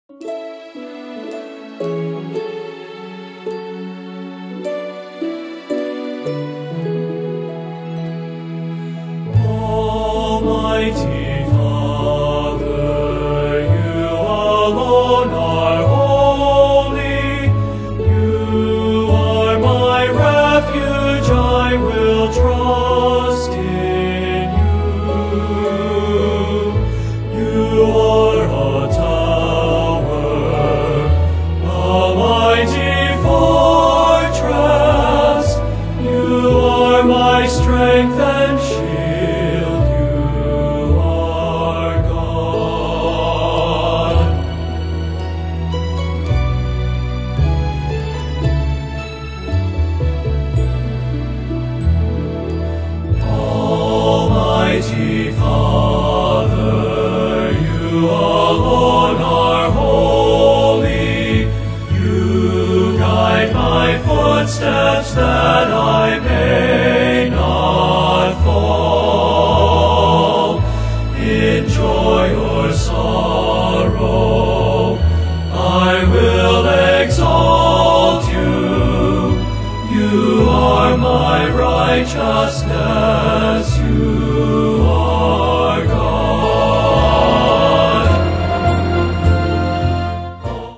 Hymns & Songs  ( Music Clips )